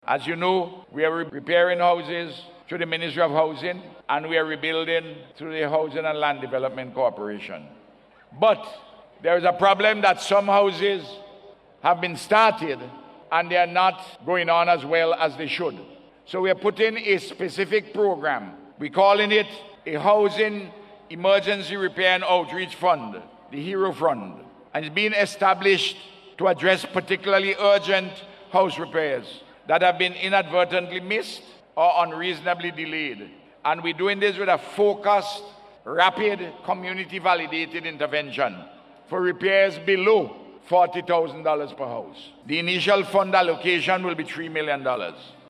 This was disclosed by Prime Minister, Dr. Ralph Gonsalves, during his Independence Day Address on Monday.